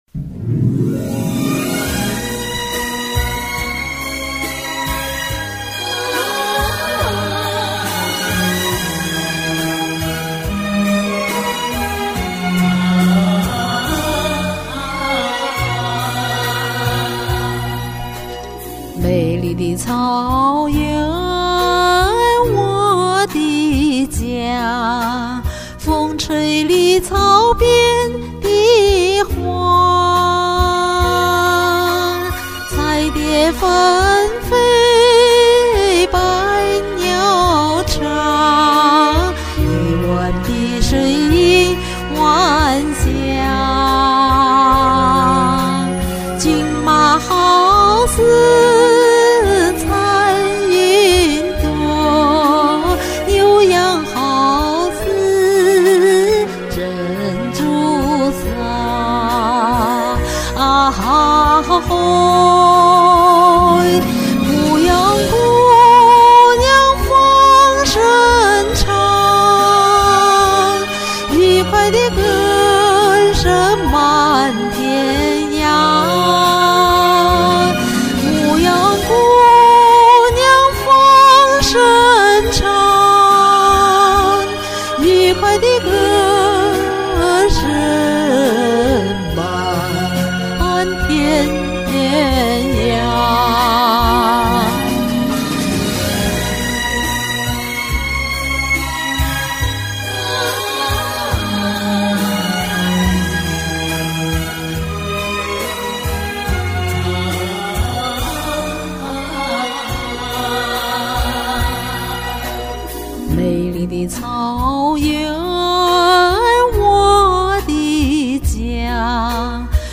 經典草原歌曲